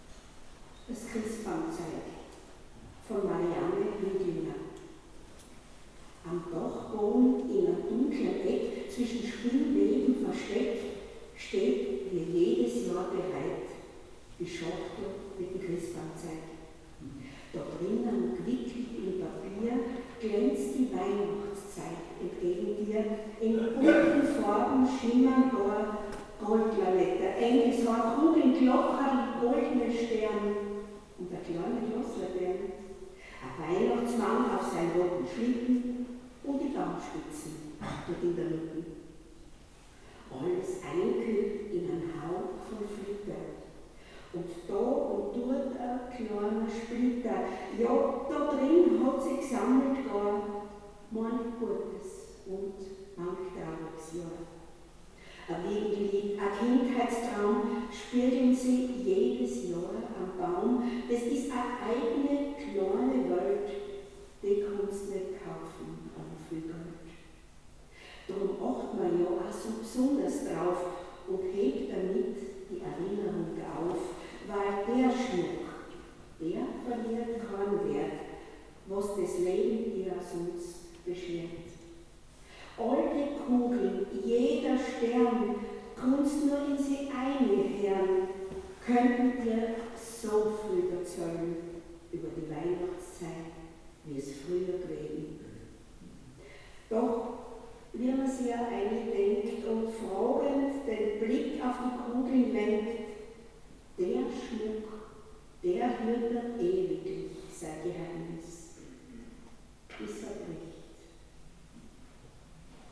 Hier ein Mitschnitt vom Adventkonzert 2022 in der Pfarrkirche Thal vom 28.11.2022